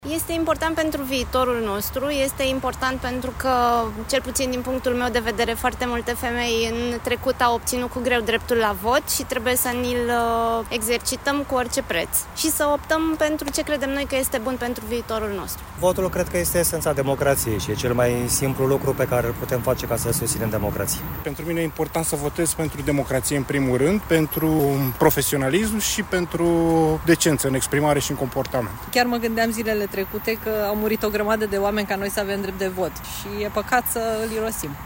De ce este important votul? Ne spun câțiva alegători din București.